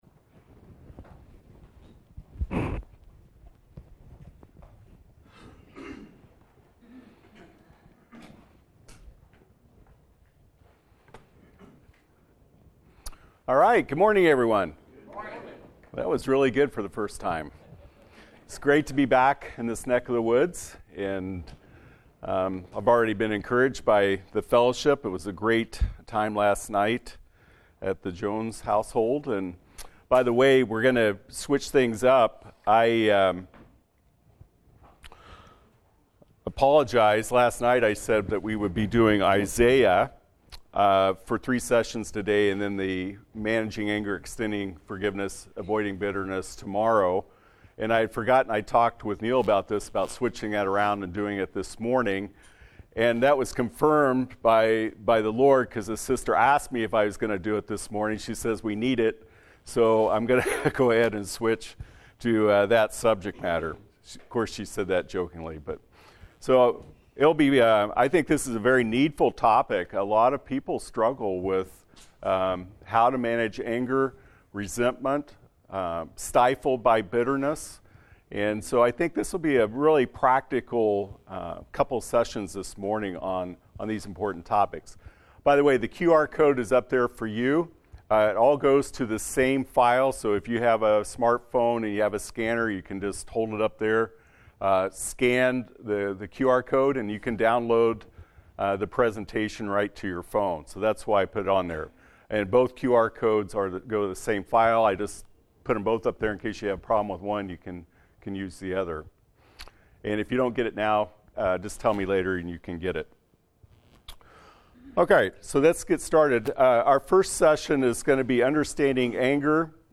Service Type: Special Meetings